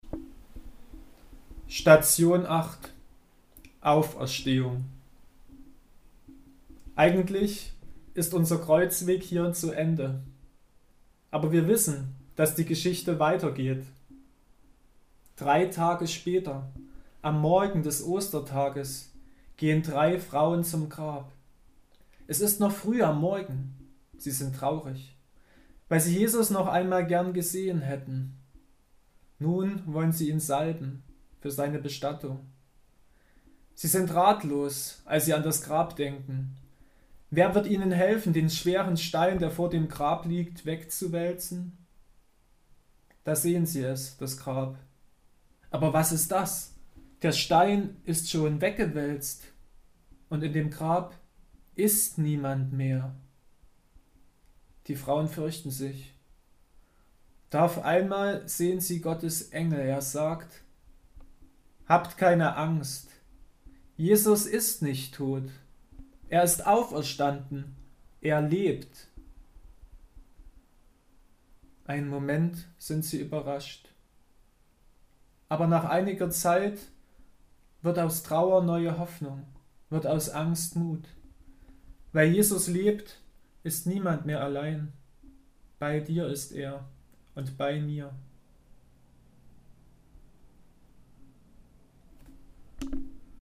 Text der Station zum Anhören